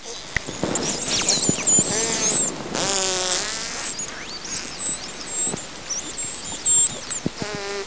Dolphin Inside Water